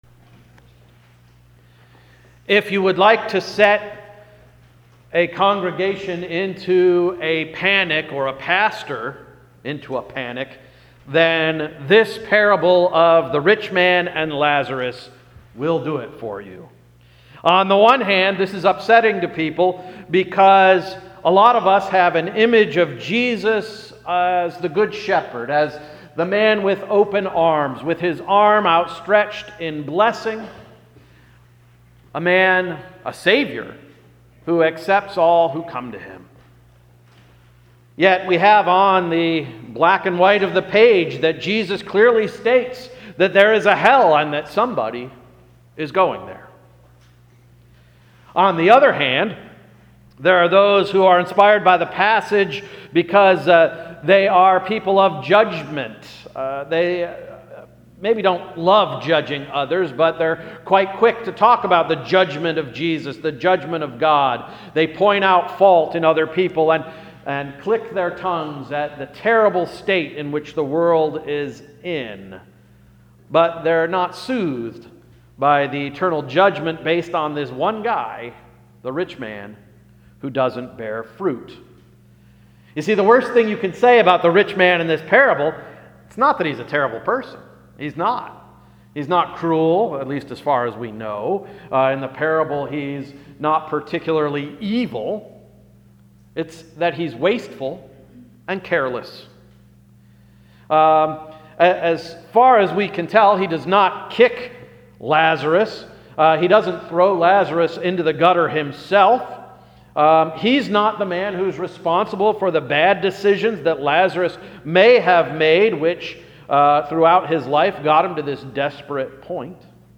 April 2, 2017 Sermon — “Stepping Over Problems”